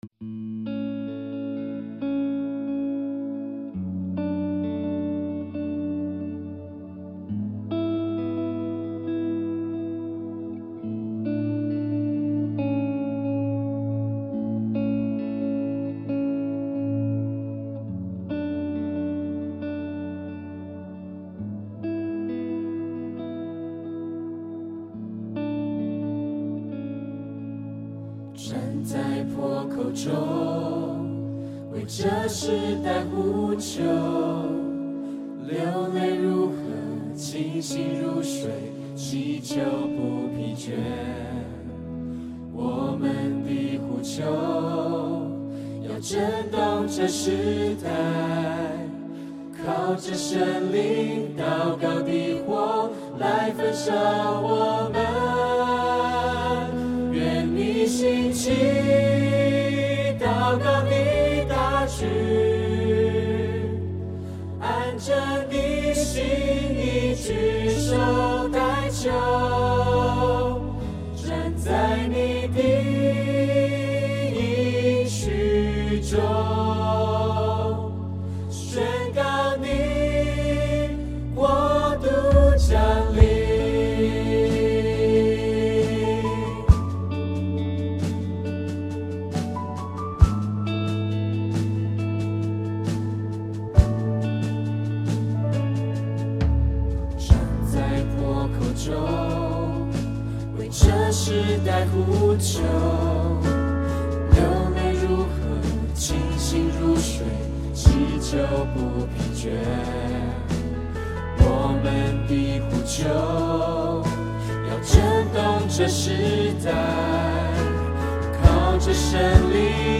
2023-06-11 敬拜诗歌 | 预告